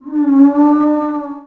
c_zombim1_hit2.wav